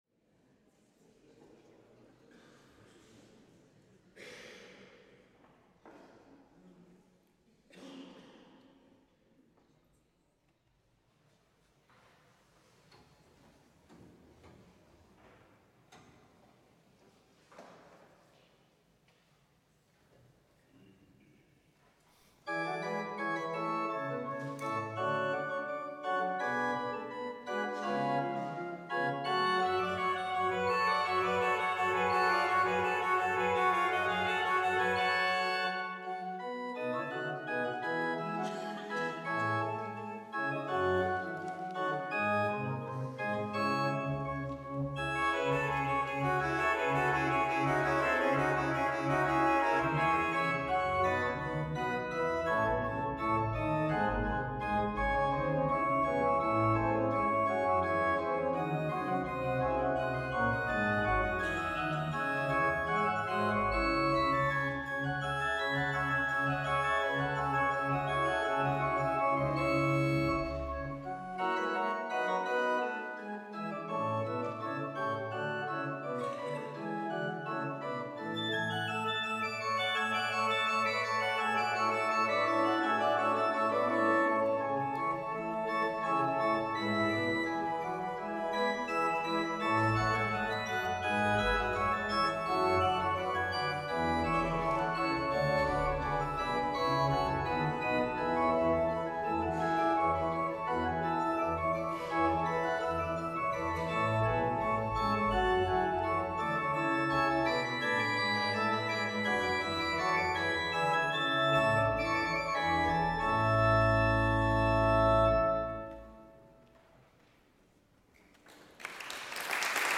I had wondered in the past about adding a touch of real noise from time to time.
(PMI-Organ, VSL Solo-Violin,  Noises from my recordings)